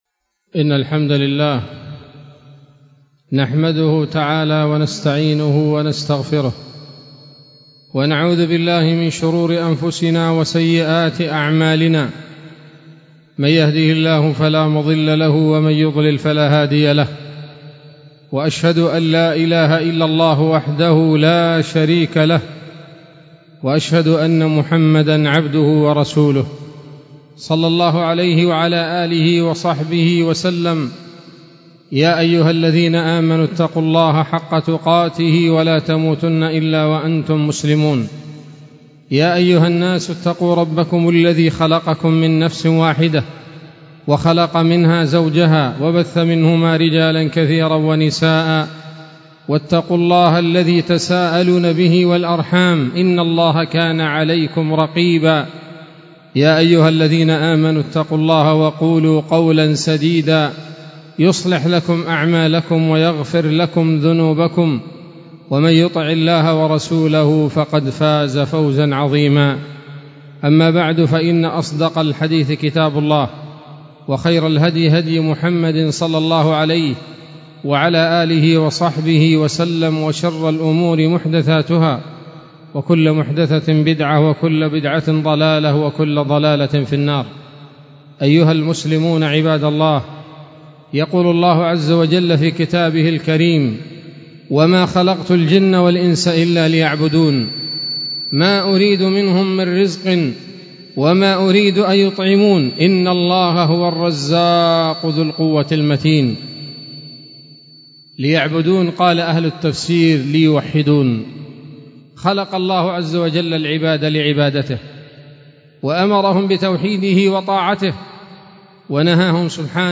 خطبة جمعة بعنوان: (( أثر المعاصي على الفرد والمجتمع )) 6 محرم 1446 هـ، دار الحديث السلفية بصلاح الدين